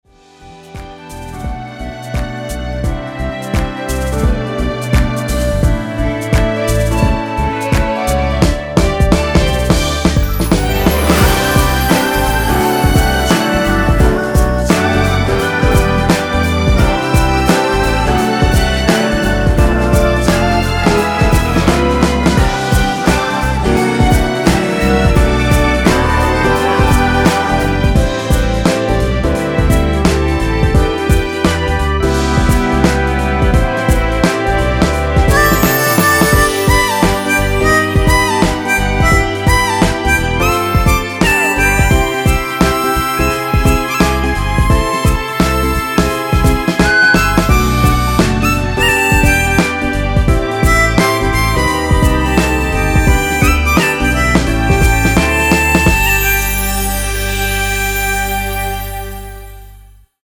엔딩이 페이드 아웃이라서 노래하기 편하게 엔딩을 만들어 놓았으니 미리듣기 확인하여주세요!
원키에서(-1)내린 멜로디와 코러스 포함된 MR입니다.
앞부분30초, 뒷부분30초씩 편집해서 올려 드리고 있습니다.
중간에 음이 끈어지고 다시 나오는 이유는